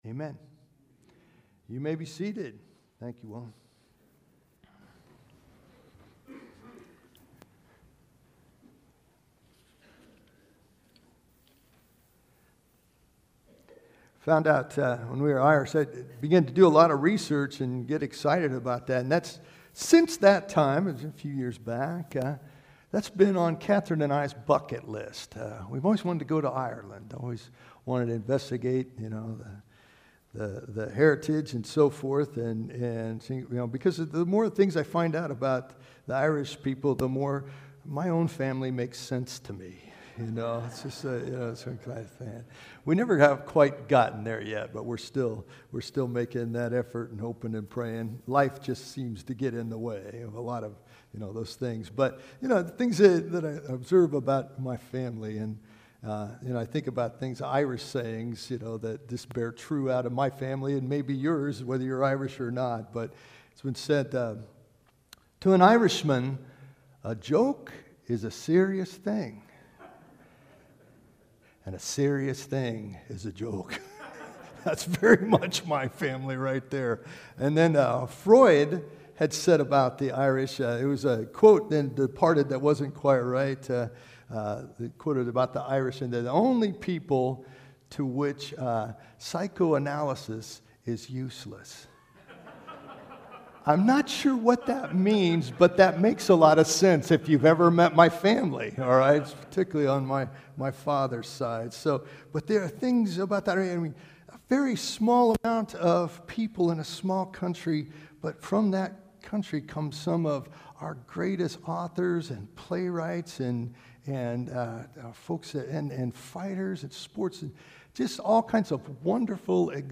Second Sunday of Lent. In this season leading up to Easter, we learn to Love God, Love each Other, and Change the World.